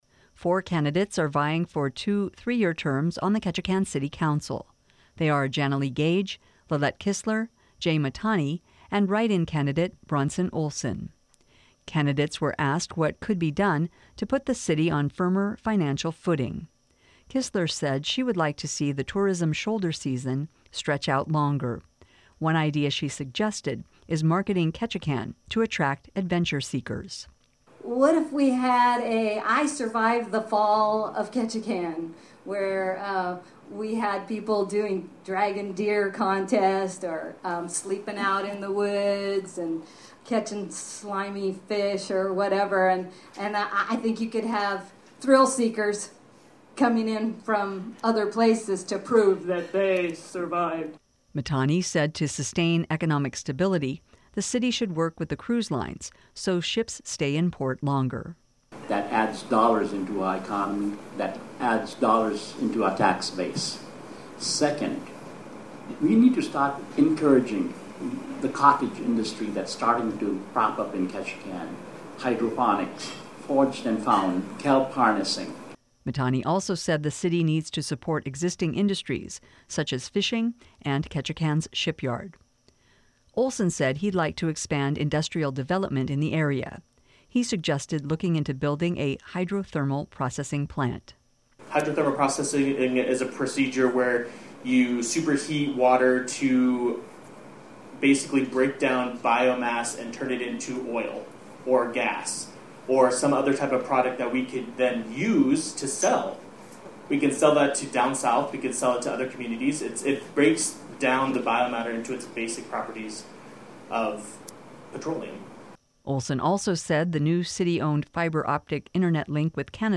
In the final Greater Ketchikan Chamber of Commerce forum of this election season, candidates for Ketchikan City Council shared their views on a variety of topics. Here are highlights.